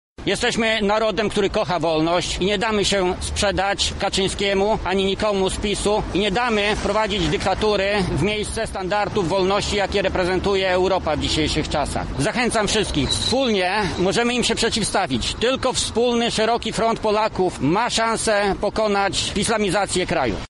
Akcją bilbordową chce pokazać, że Polacy powinni wspólnie stanąć w obronie naszej wolności – tłumaczy senator Jacek Bury: